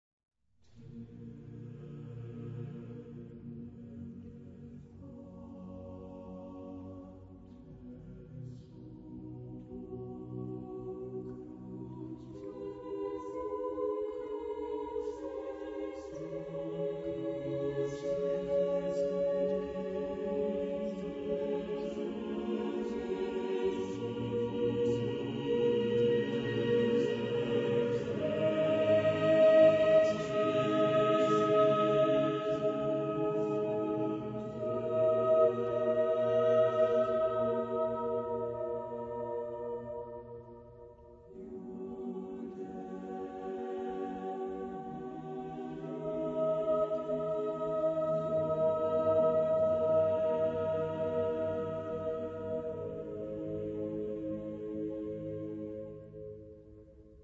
Genre-Style-Forme : Sacré ; Baroque ; Motet
Type de choeur : SAATTB OU SSATTB  (6 voix mixtes )
Tonalité : la mode de mi